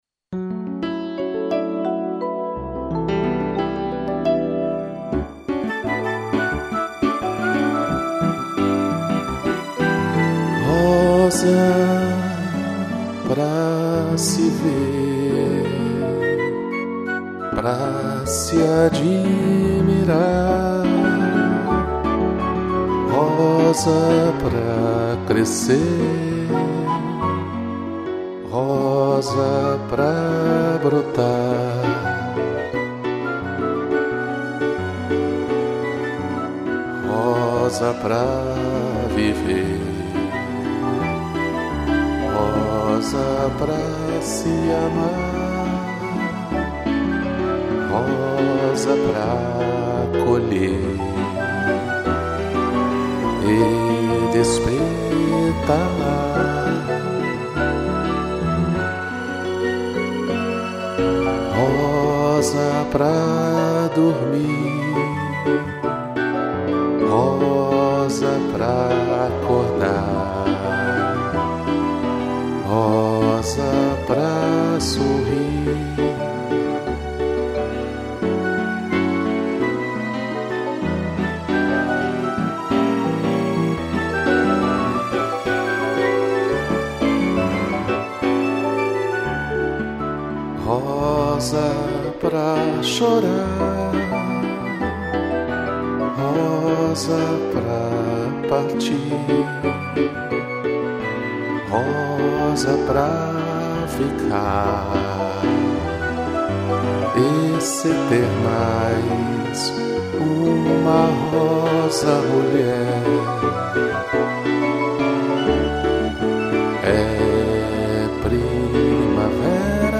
piano, marimba, strings e flauta